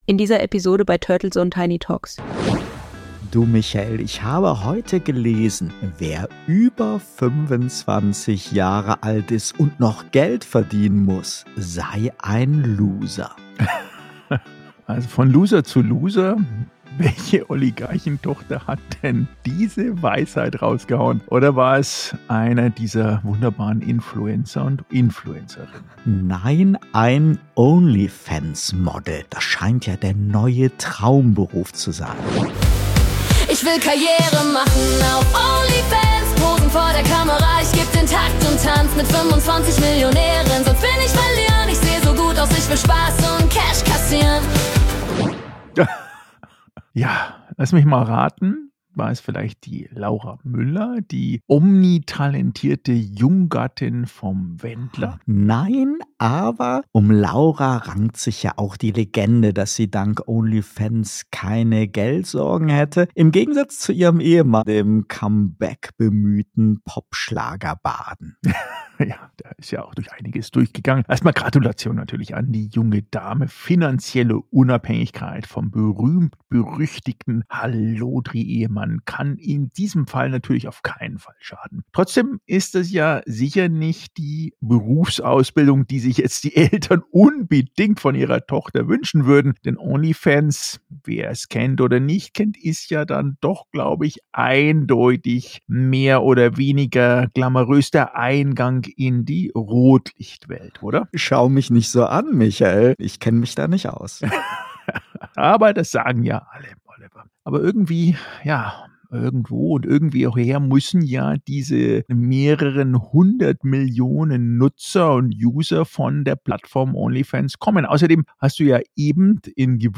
Der Debatten-Podcast